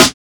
boom bap (snare).wav